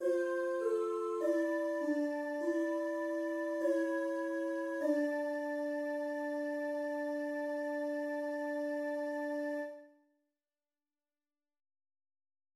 Clausula vera из Beatus homo Орландо ди Лассо, такты 34–35[28].
Clausula vera («истинное завершение») — диадическая каденция: два голоса движутся навстречу друг другу по ступеням в противоположных направлениях, сходясь в октаву или унисон[28].